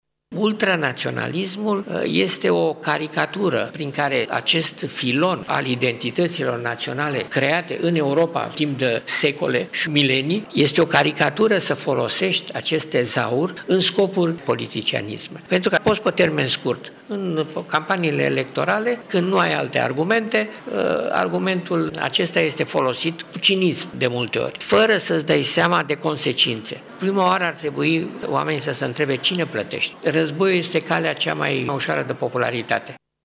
El a declarat azi, la Tîrgu-Mureș, la seminarul ”Retrospectivă și perspectivă”, că trebuie făcută distincția între naționalismul luminat de la sfârșitul Primului Război Mondial și extremismele de acum.